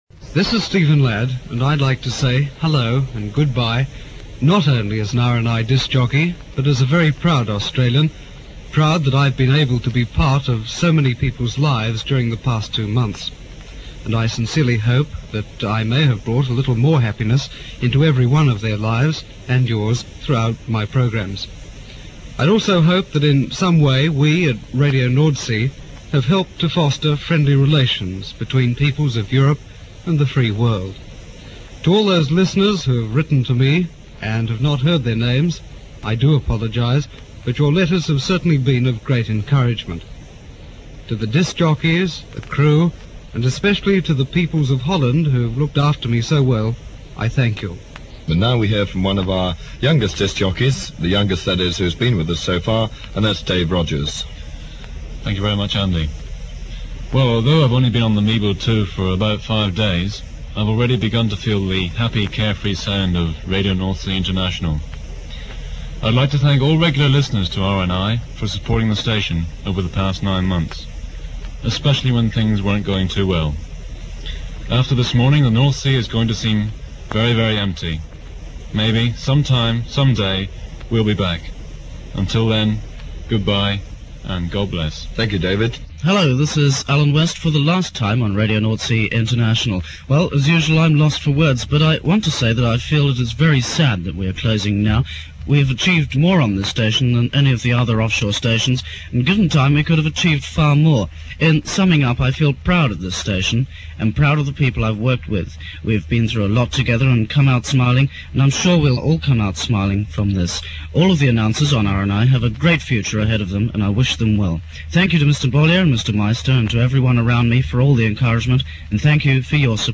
click to hear audio The onboard disc-jockeys say their farewells (duration 4 minutes 38 seconds)